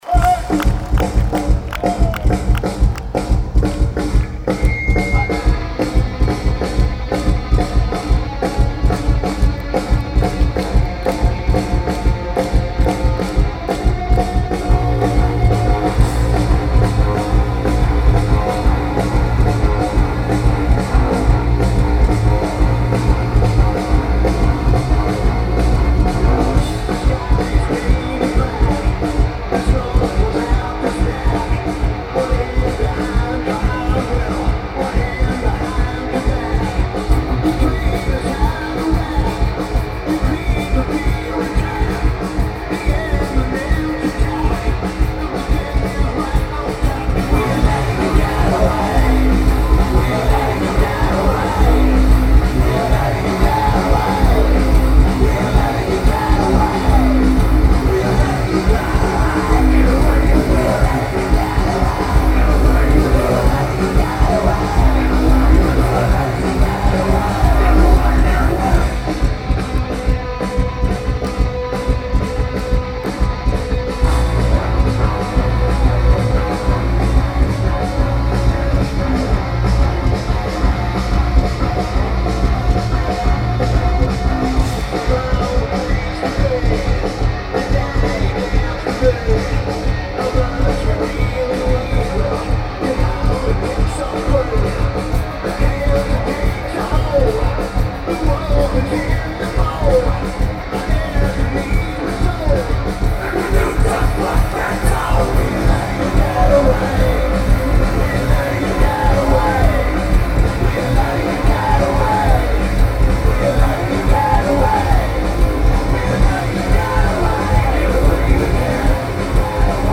Phones 4u Arena